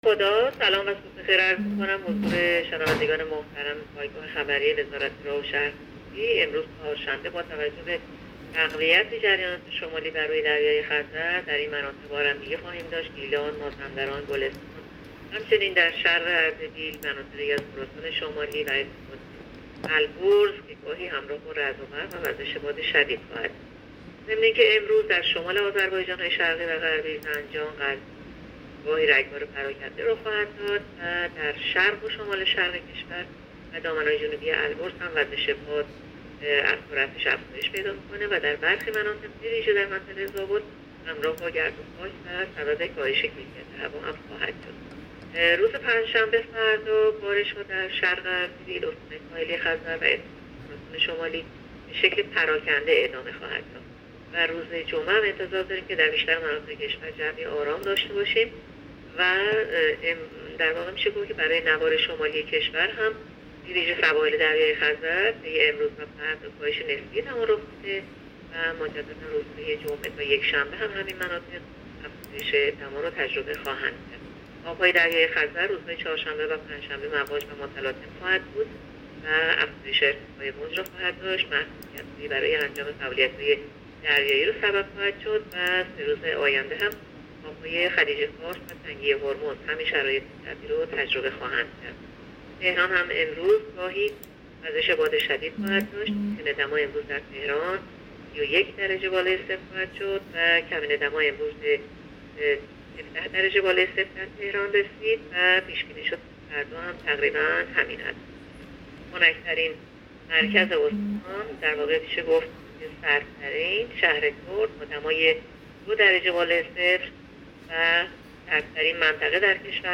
گزارش رادیو اینترنتی پایگاه‌ خبری از آخرین وضعیت آب‌وهوای نهم مهر؛